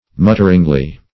Search Result for " mutteringly" : The Collaborative International Dictionary of English v.0.48: Mutteringly \Mut"ter*ing*ly\, adv.